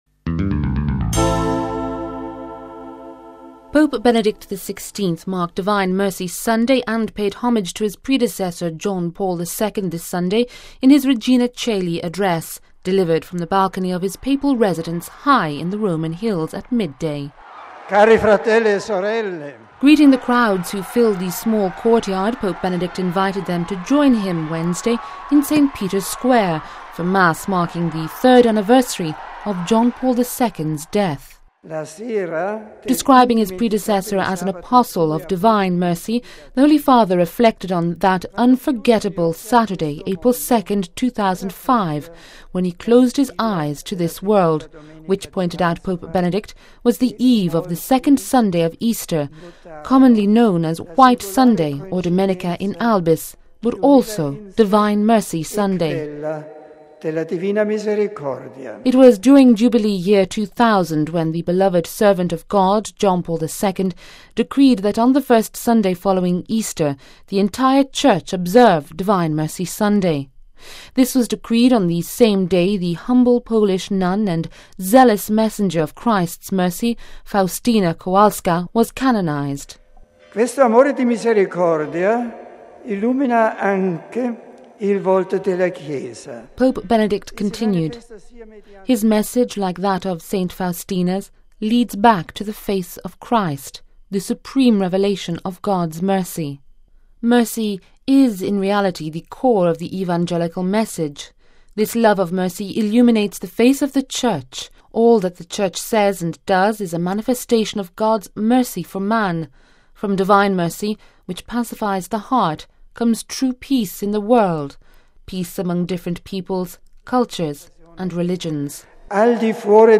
Pope Benedict XVI’s marked Divine Mercy Sunday and paid homage to his predecessor John Paul II this Sunday in his Regina Ceoli address, delivered from the Balcony of his papal residence high in the Roman hills at midday.
Greeting the crowds who filled the small courtyard, Pope Benedict invited them to join him Wednesday in St Peter’s square, for mass marking the third anniversary of the John Paul II’s death.